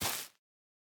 Minecraft Version Minecraft Version 25w18a Latest Release | Latest Snapshot 25w18a / assets / minecraft / sounds / block / spore_blossom / step5.ogg Compare With Compare With Latest Release | Latest Snapshot
step5.ogg